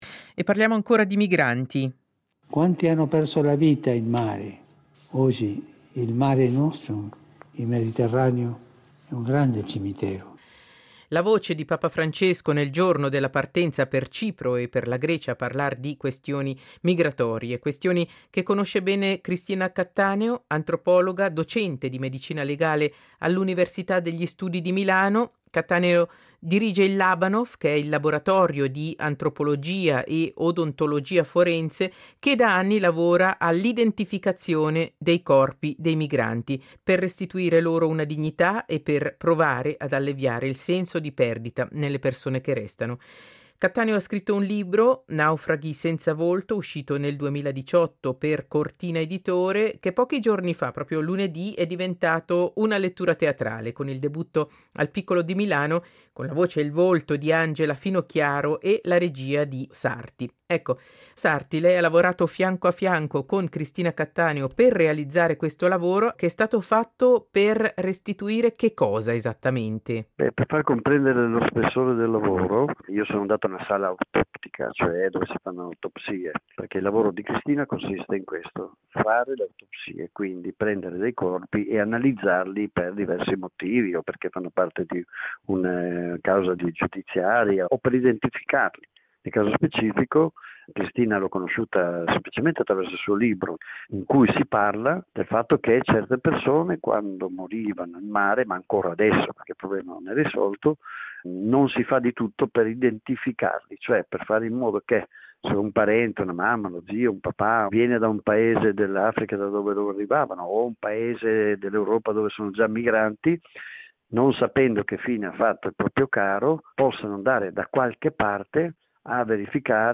SEIDISERA del 02.12.21: Intervista